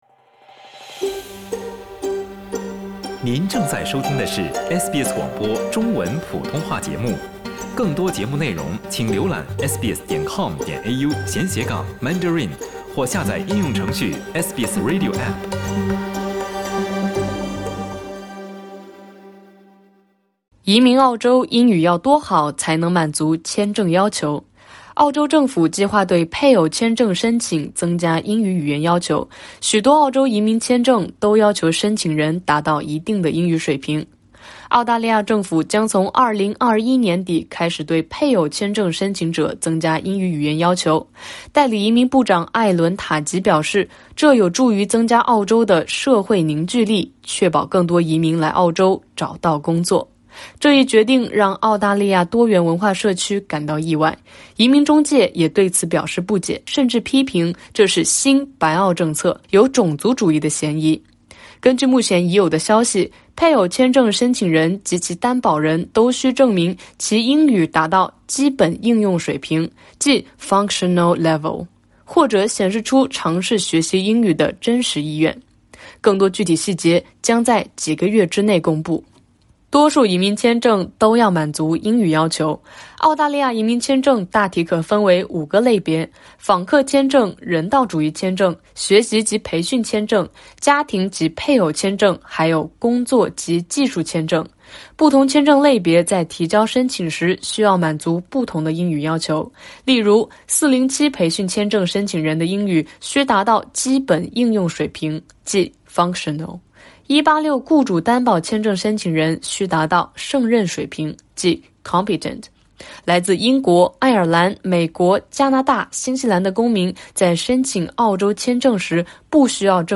本录音报道将详细就上述细节进行介绍。